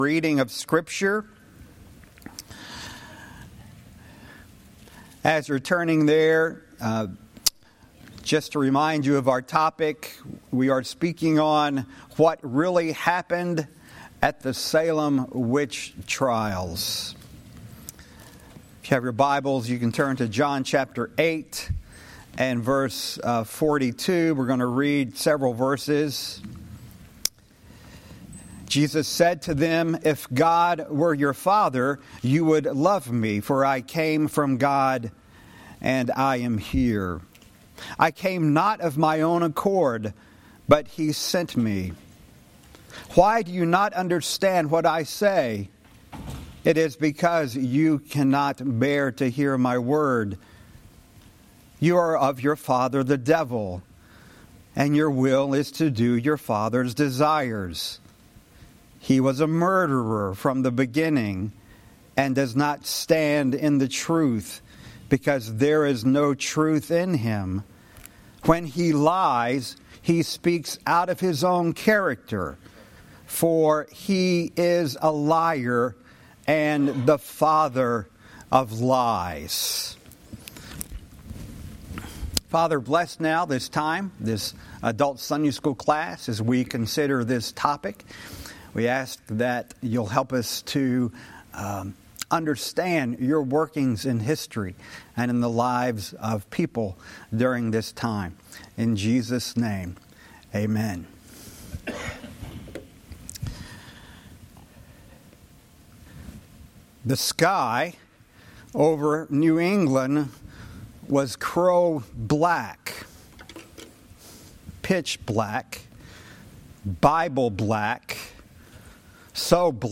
Adult Sunday School
Salem-Witch-Trial-Sunday-School.mp3